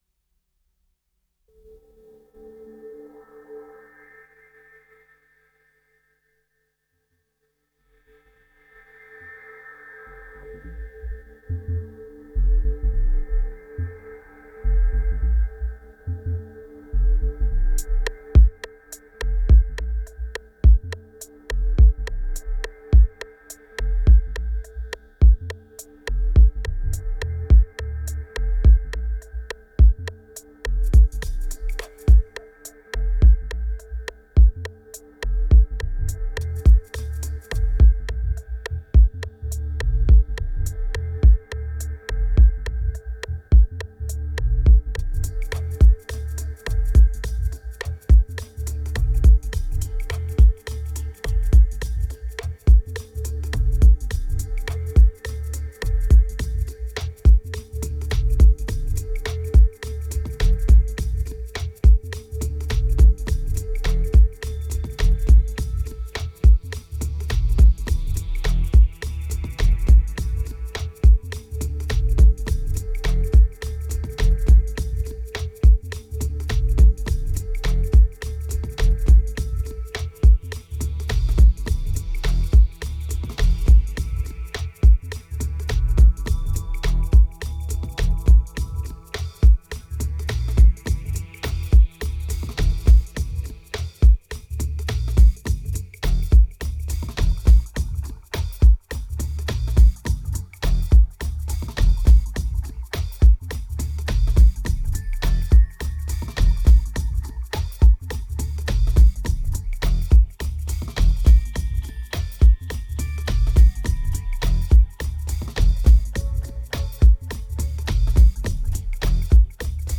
1954📈 - 36%🤔 - 105BPM🔊 - 2010-04-07📅 - -38🌟